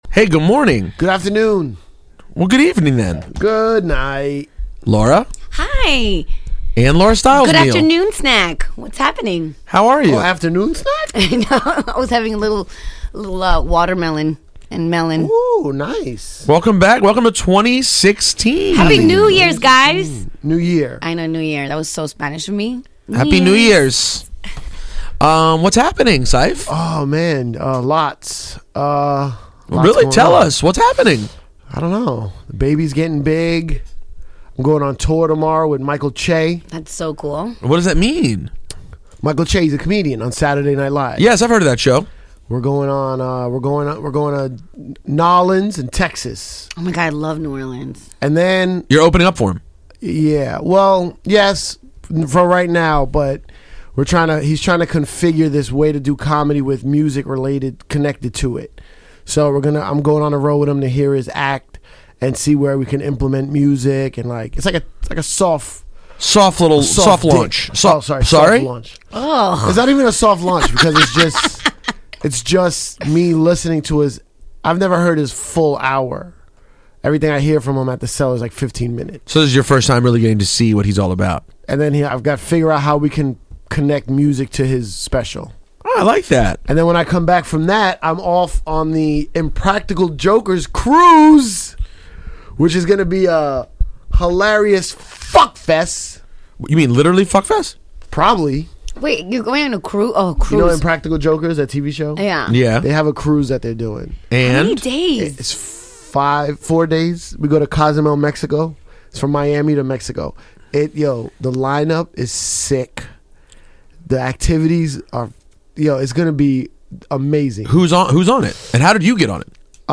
so we decided to do something different We spent 80 minutes playing awesome random hip hop (mostly from the 90s) and talking about it.